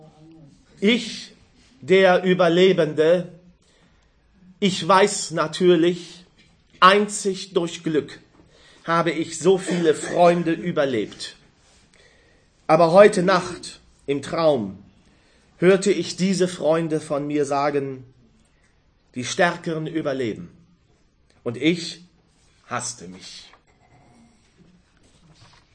Am Samstag, den 10. November 2012 war im Mädchen- und Frauenzentrum Courage Essen e.V. (Essen ) eine Lesung mit Texten von Bertolt Brecht.
Schauspieler